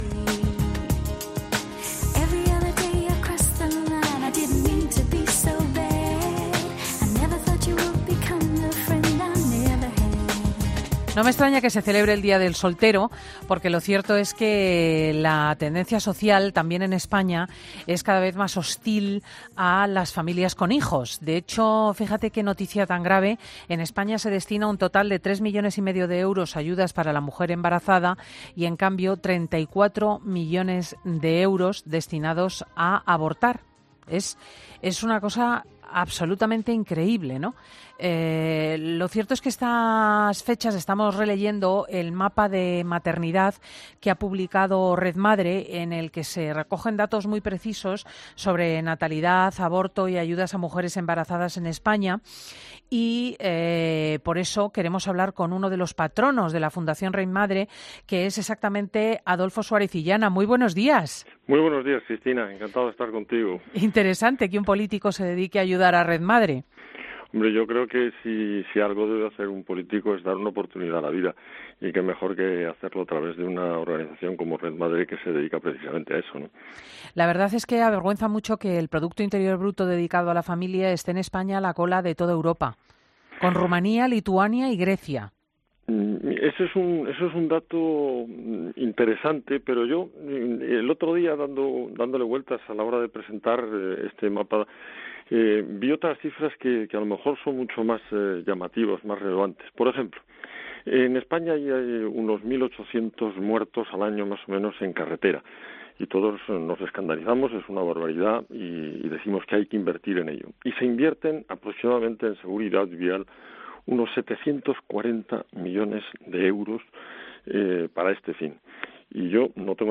Adolfo Suárez Illana, portavoz y miembro del patronato de Red Madre, habla en Fin de semana sobre el mapa de la maternidad en nuestro país